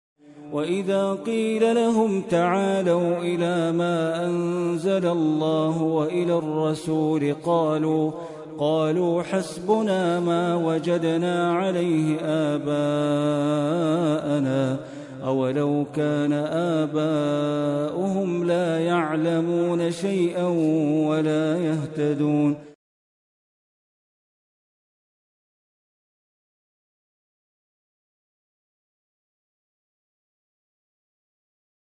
quran recitation
quran recitation beautiful voice